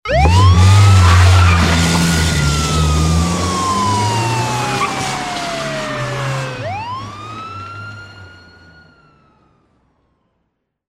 Salida rápida de un coche de policía
sirena